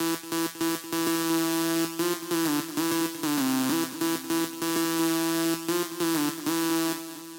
Tag: 140 bpm Dubstep Loops Synth Loops 3.46 MB wav Key : Unknown